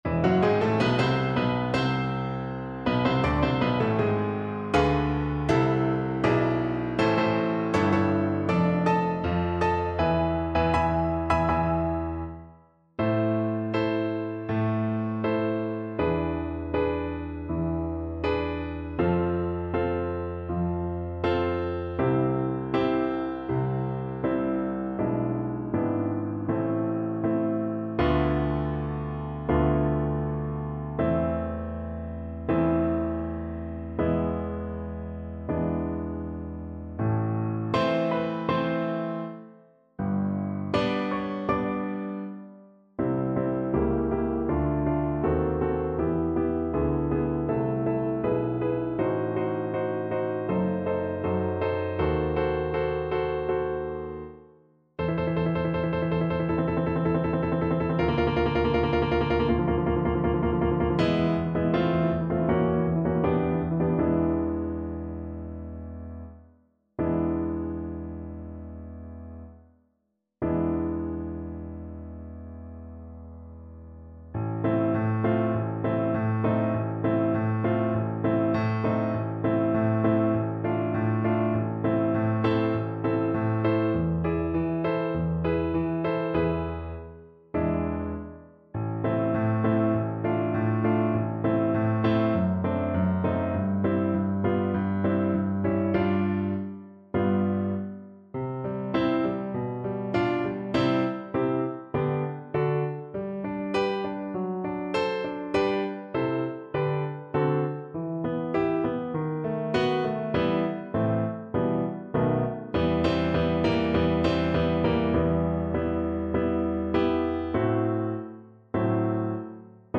Play (or use space bar on your keyboard) Pause Music Playalong - Piano Accompaniment Playalong Band Accompaniment not yet available transpose reset tempo print settings full screen
Violin
A minor (Sounding Pitch) (View more A minor Music for Violin )
Andante sostenuto
Classical (View more Classical Violin Music)